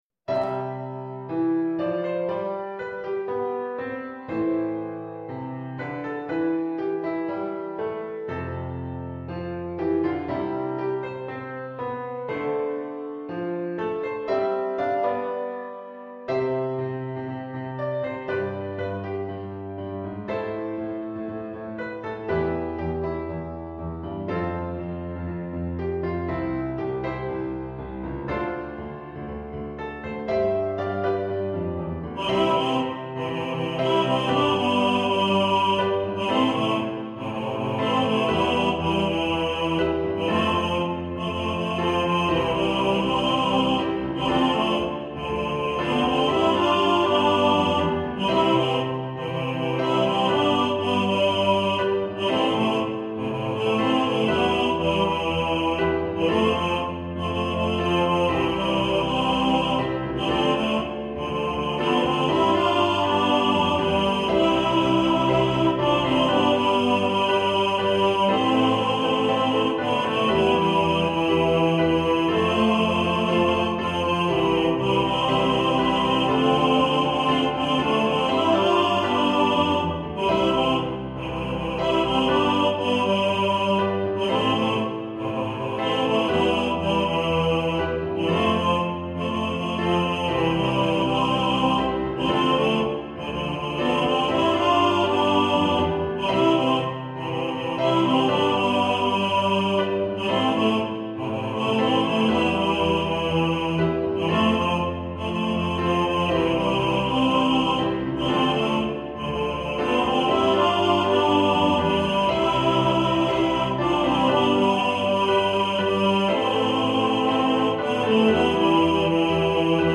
Hier erhalten Sie die dreistimmige Chorversion!